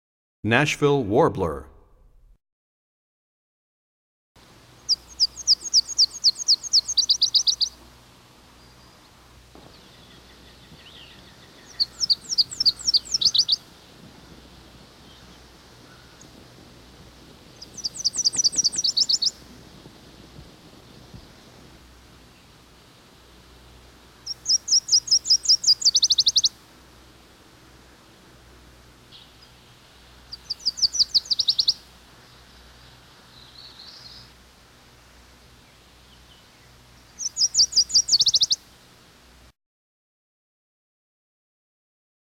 61 Nashville Warbler.mp3